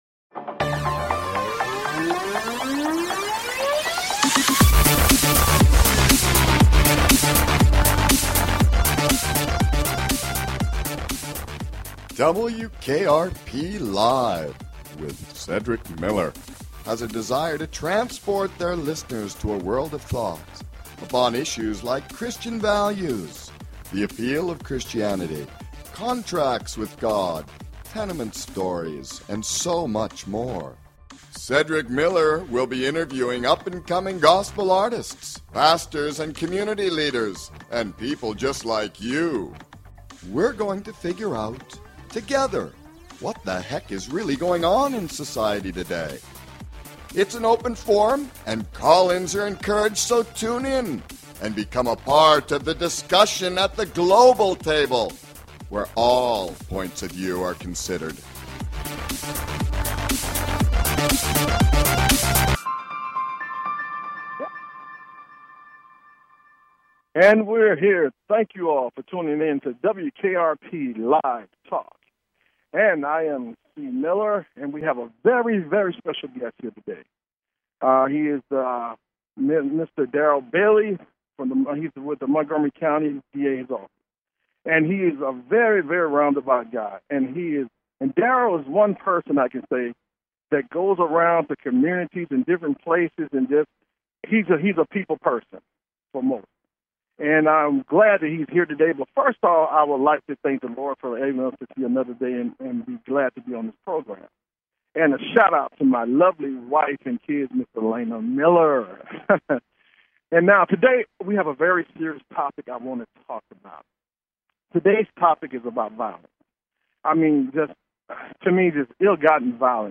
Guest, Troy King